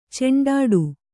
♪ ceṇḍāḍu